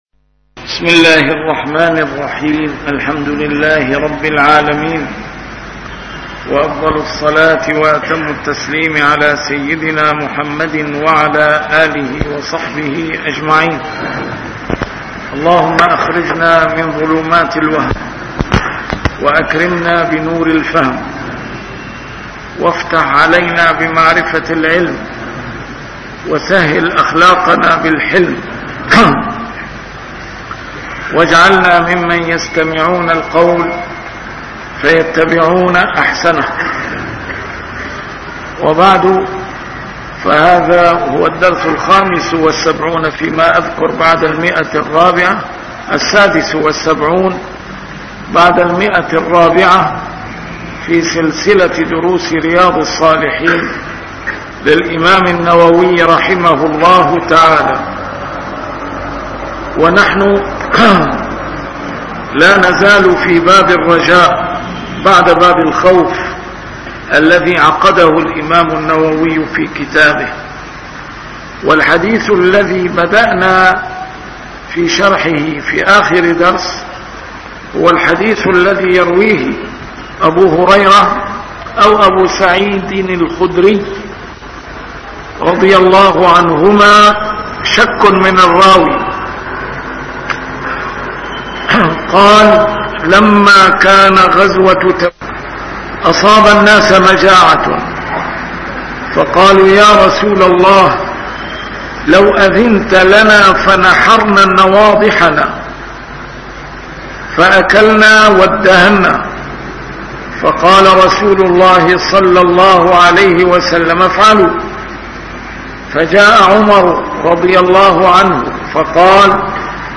A MARTYR SCHOLAR: IMAM MUHAMMAD SAEED RAMADAN AL-BOUTI - الدروس العلمية - شرح كتاب رياض الصالحين - 476- شرح رياض الصالحين: الرجاء